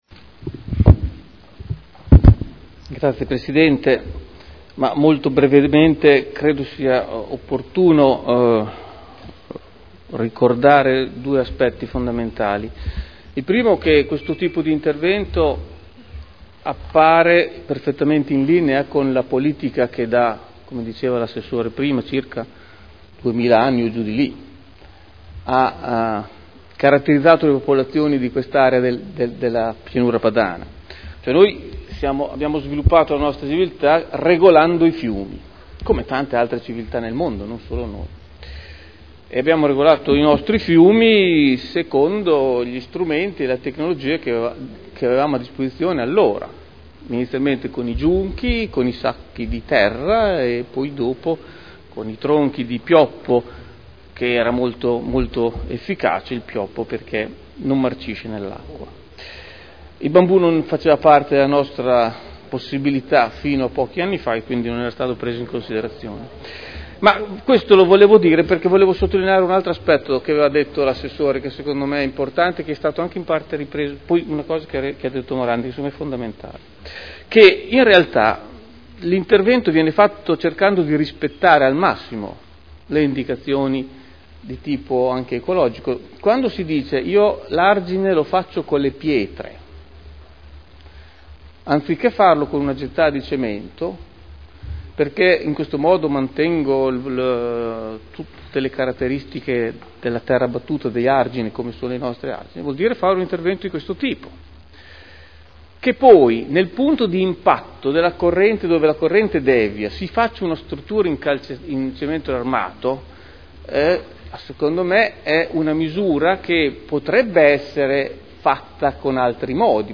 Luigi Alberto Pini — Sito Audio Consiglio Comunale
Dichiarazioni di voto